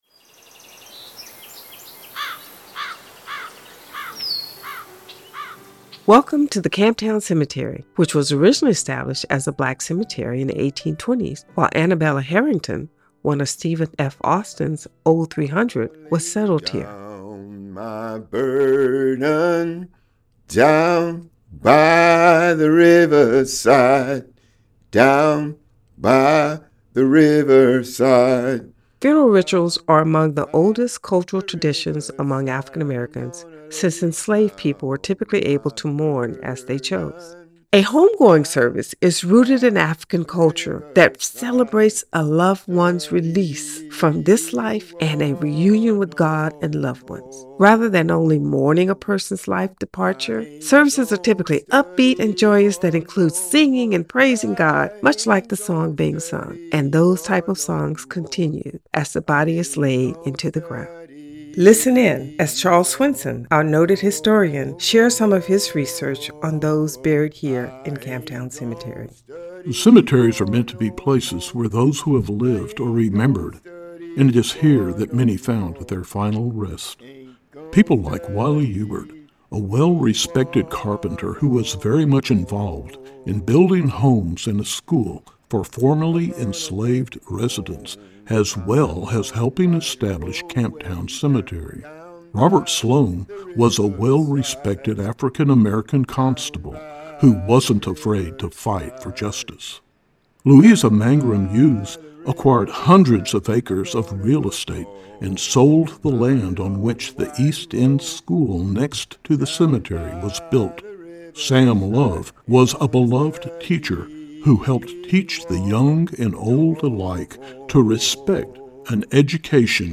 You will go on a tour that includes sounds and stories of the area.